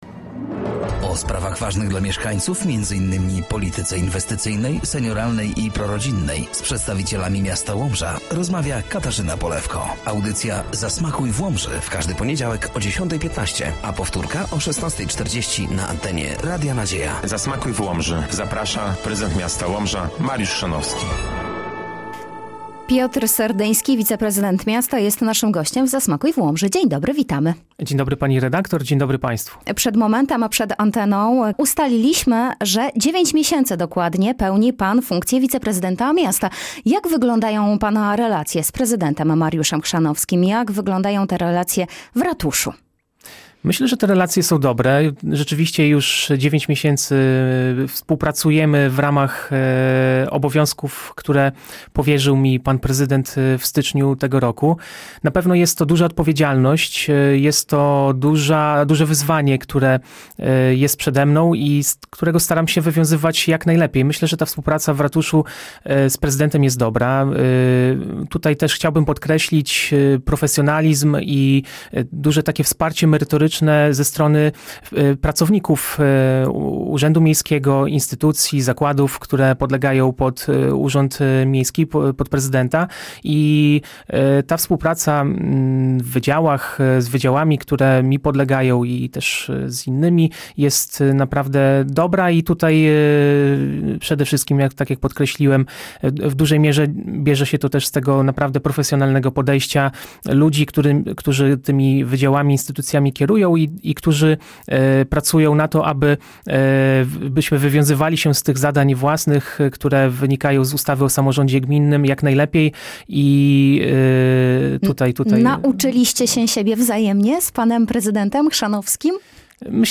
Na antenie Radia Nadzieja gościł wiceprezydent Łomży Piotr Serdyński.